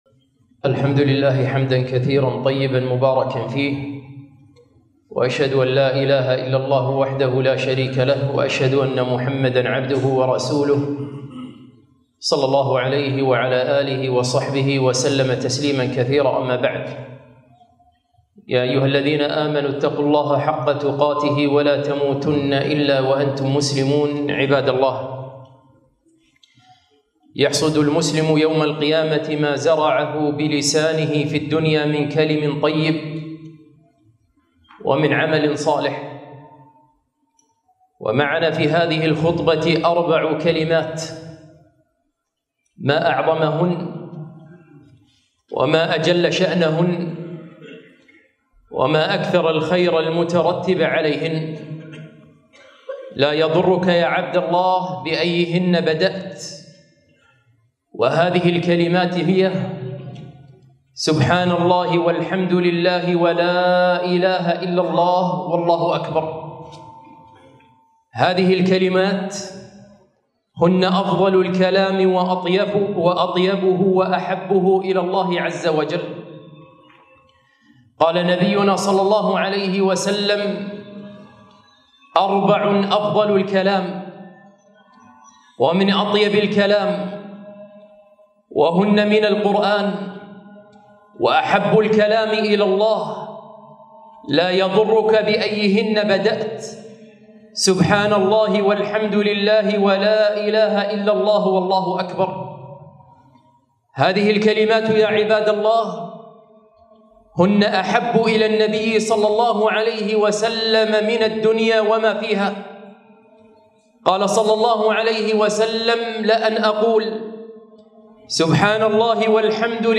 خطبة - فضائل الكلمات الأربع